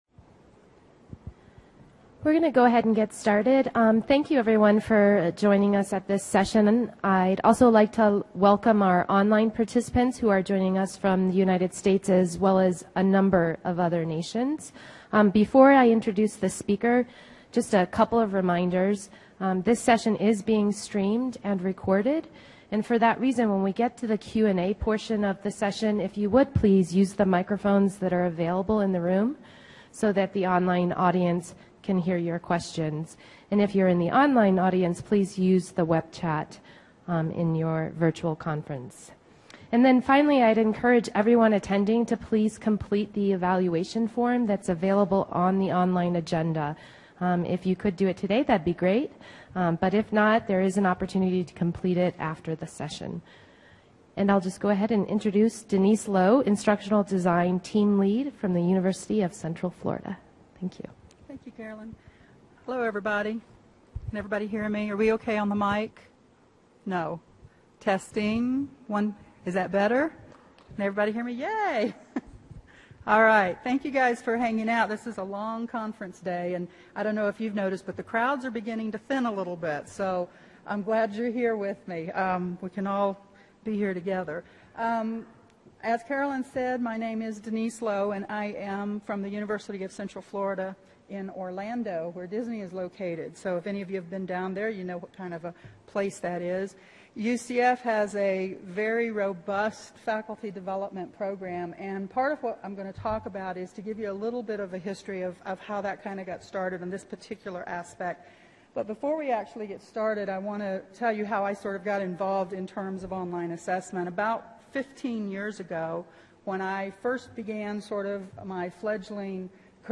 The presenter will provide discipline-specific examples, provide opportunities for practice application, and encourage an open discussion regarding authentic assessment.